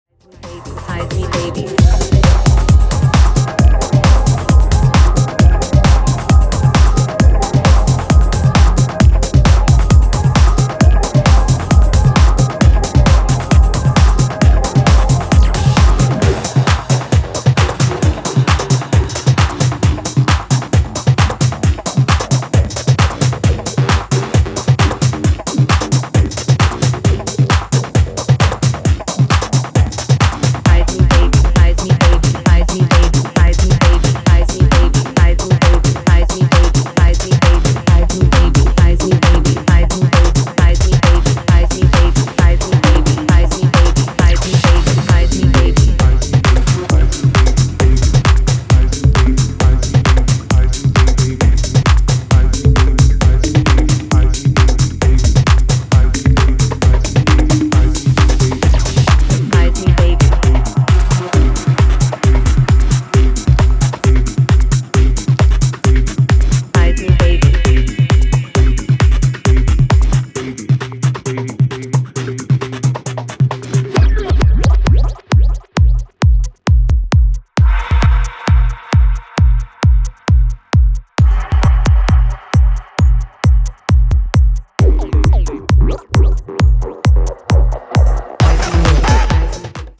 bounce, bass and..
House , Techno